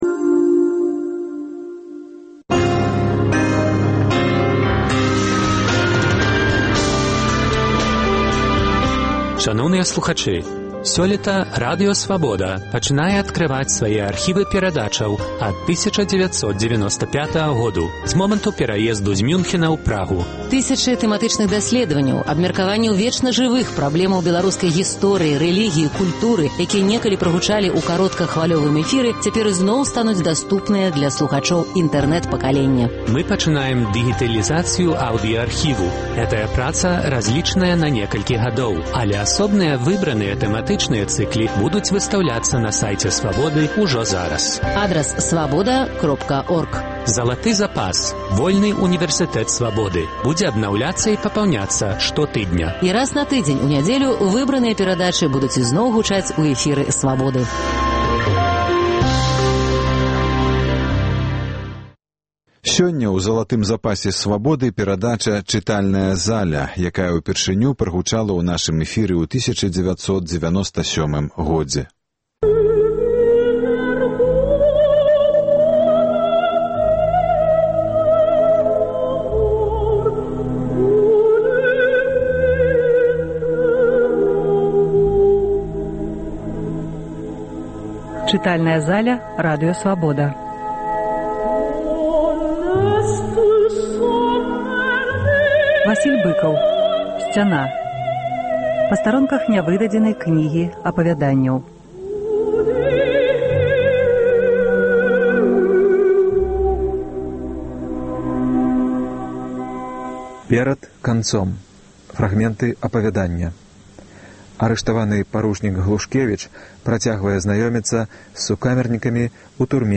Васіль Быкаў чытае апавяданьні з кнігі "Сьцяна". Архіўныя запісы 1997 году.